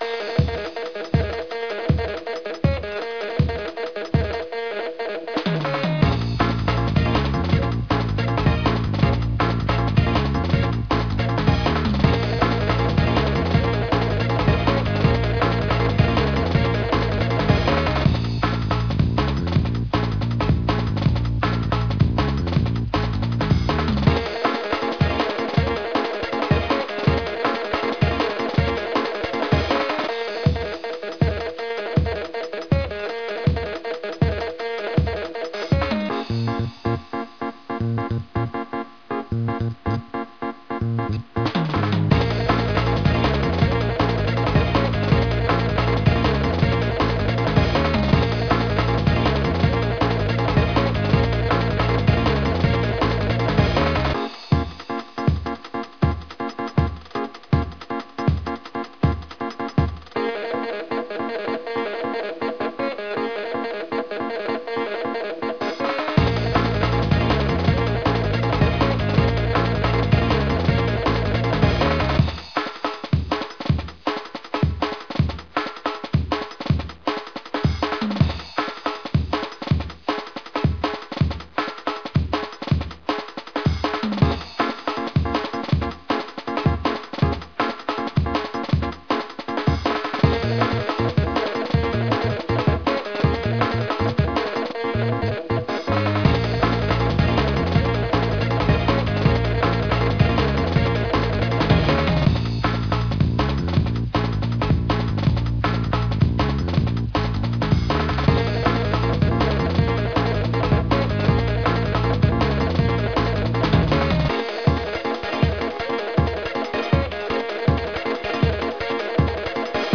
Type BGM
Speed 140%